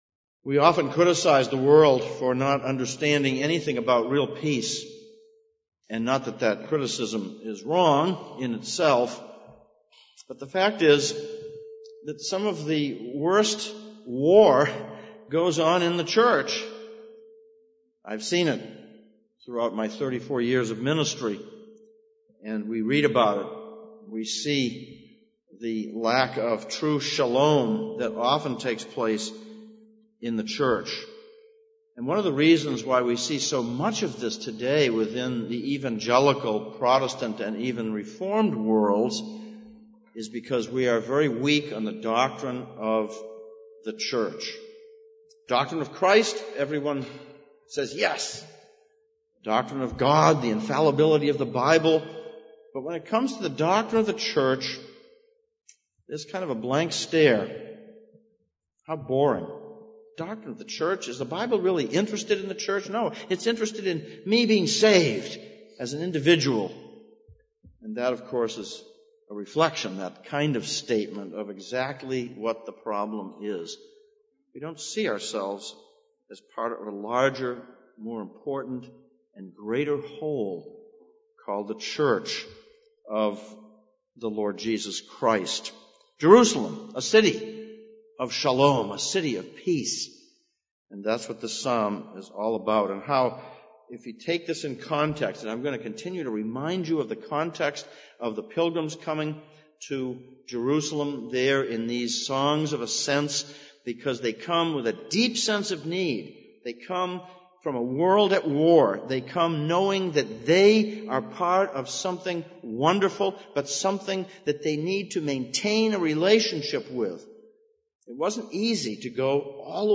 Psalms of Ascents Passage: Psalm 122:1-9, Ephesians 4:1-16 Service Type: Sunday Morning « 1.